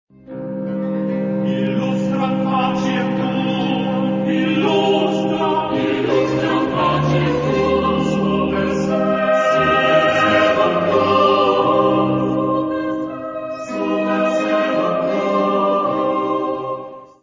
Género/Estilo/Forma: Cantata ; Sagrado
Carácter de la pieza : piadoso ; ferviente
Tipo de formación coral: SSATB  (5 voces Coro mixto )
Solistas : ATB  (3 solista(s) )
Instrumentos: Bajo Continuo ; Violín (2) ; Violone
Tonalidad : do menor